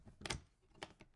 插销01
描述：一个小锁扣被解开。
标签： 锁存器
声道立体声